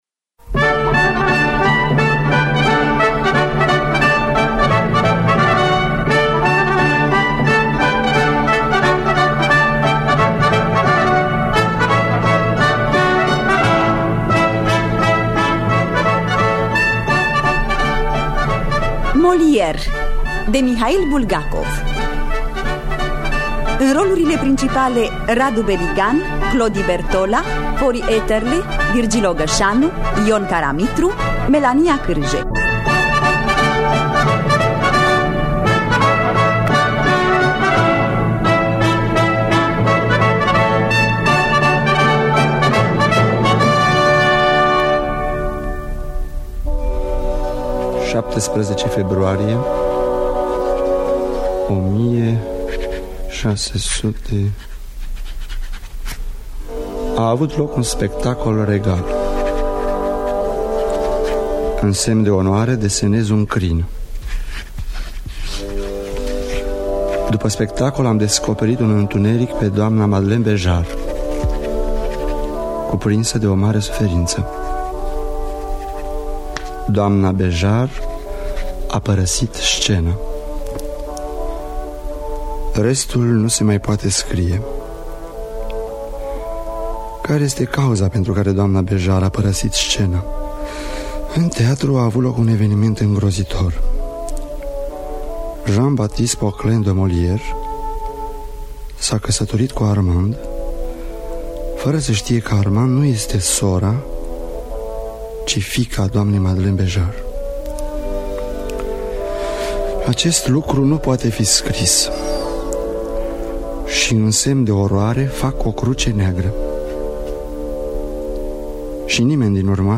Traducerea și adaptarea radiofonică